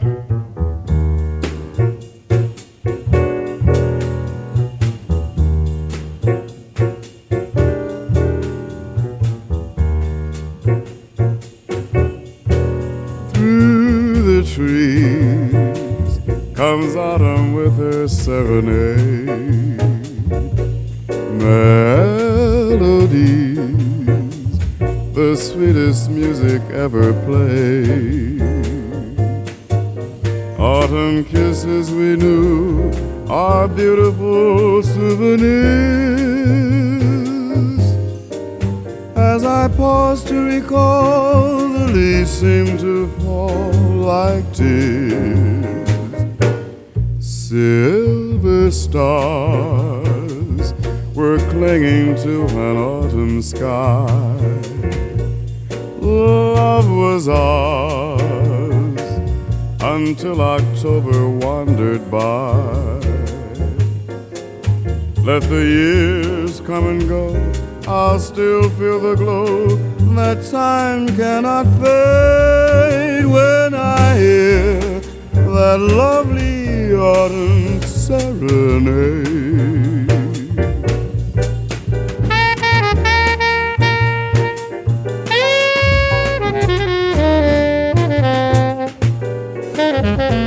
JAZZ / DANCEFLOOR / SOUL JAZZ / BIG BAND
カリプソ・ジャズ
ソウルフルなビッグバンドを従えて60'Sポップス・ヒットを中心に取り上げたソウル・ジャズな一枚！